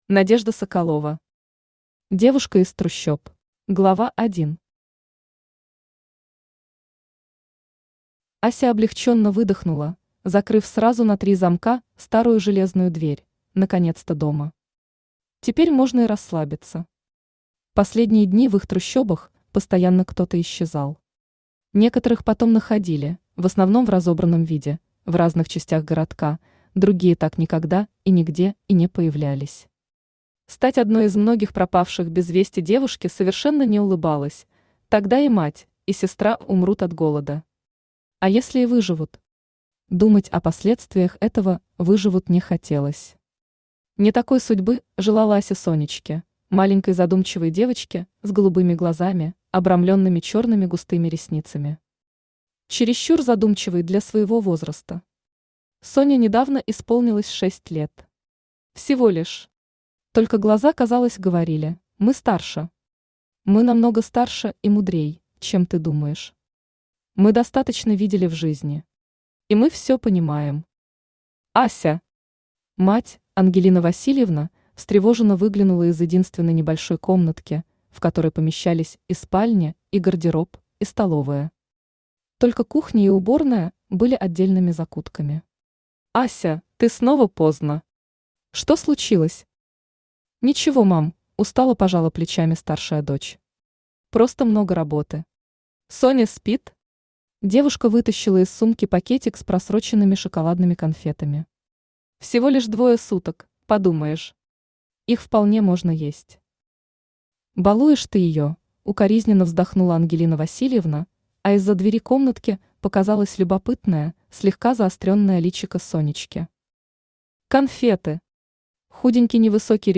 Аудиокнига Девушка из трущоб | Библиотека аудиокниг
Aудиокнига Девушка из трущоб Автор Надежда Игоревна Соколова Читает аудиокнигу Авточтец ЛитРес.